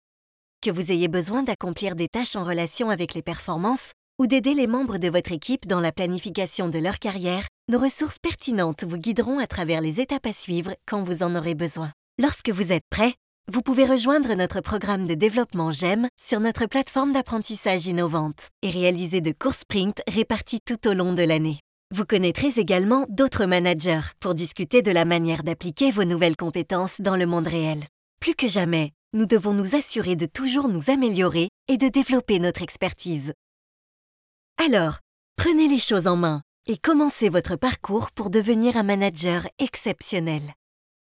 Jong, Zacht, Speels, Stoer, Veelzijdig
E-learning